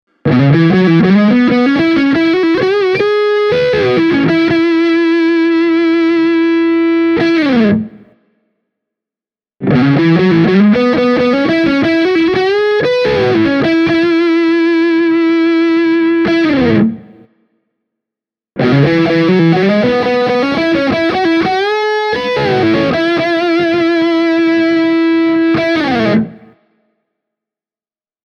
Testasin Carvin Legacy 3 -nupin vaahterakaulaisella Fender Stratocasterilla ja Hamer USA Studio Custom -kitaralla Zilla Cabsin 2 x 12” -kaapin kautta, ja tulokset puhuvat hyvin selkeää kieltä – tässä on kyseessä pro-luokan vahvistin.
Hamer Studio Custom – kanava 3